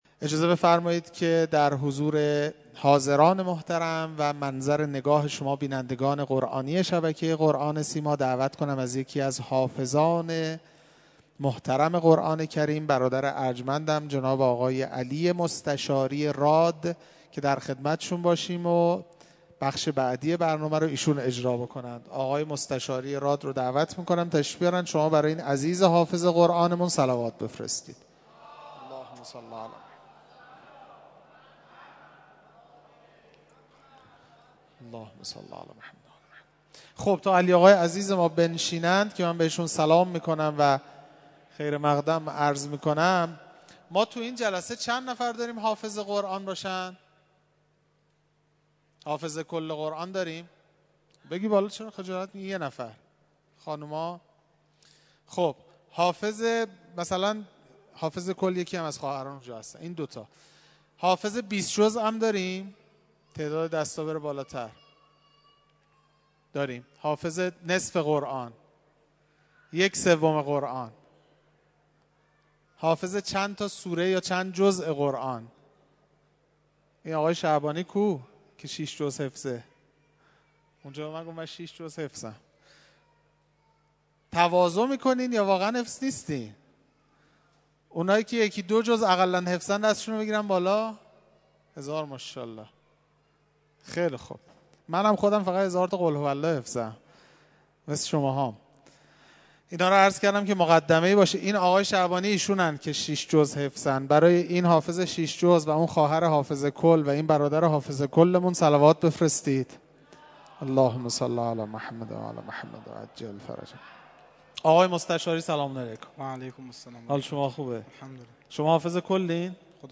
محفل انس با قرآن کریم